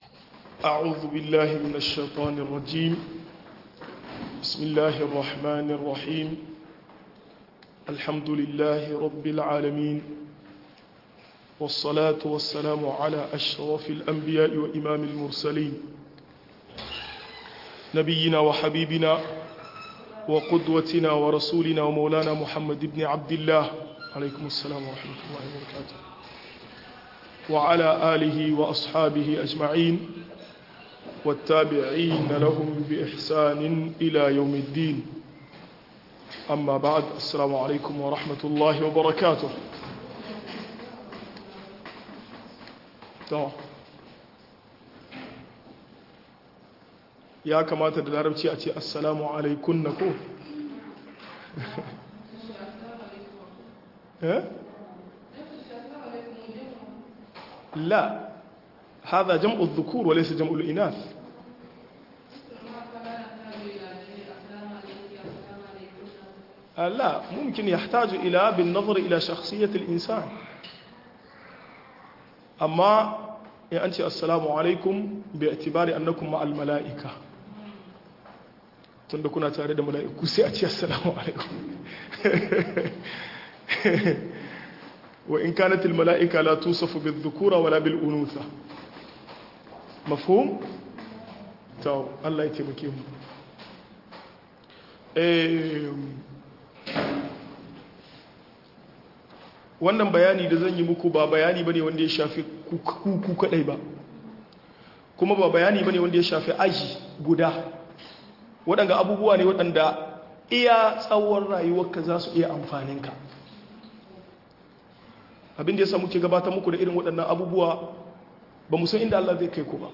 Matakan da mai tafsir Yakamata ya bi - MUHADARA